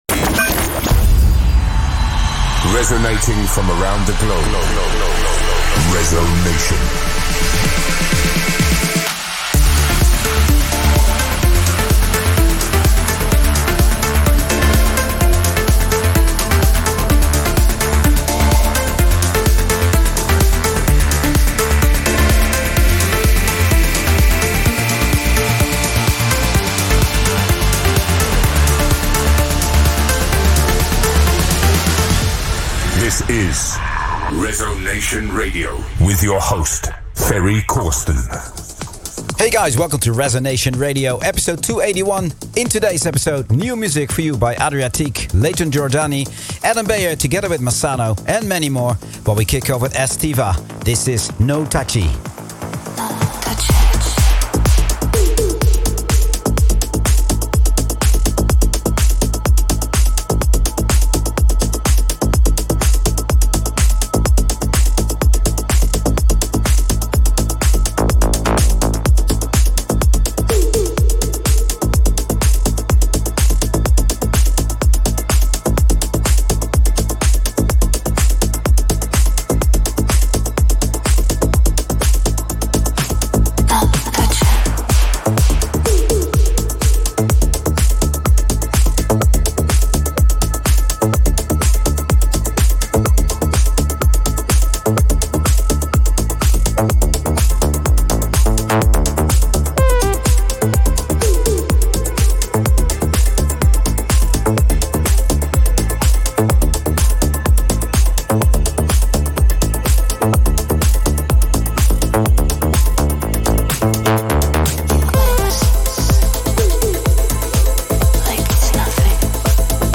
Also find other EDM Livesets, DJ Mixes and Radio Show